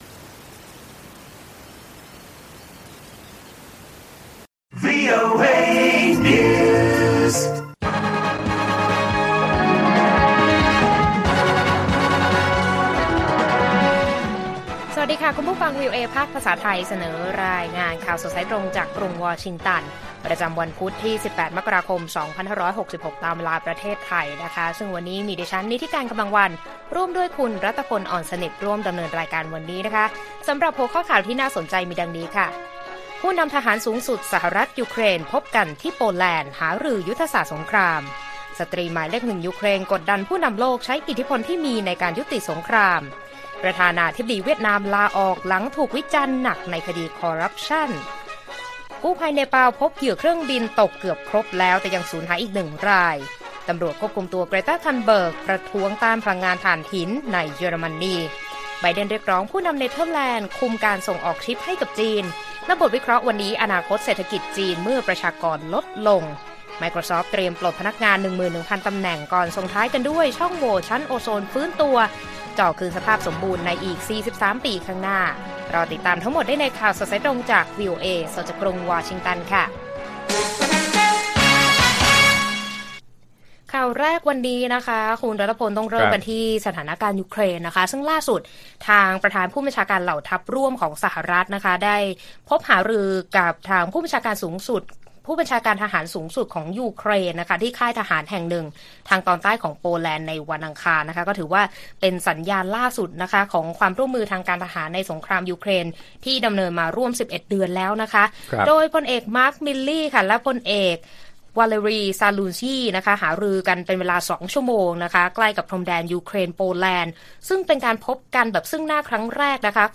ข่าวสดสายตรงจากวีโอเอไทย พุธ ที่ 18 ม.ค. 66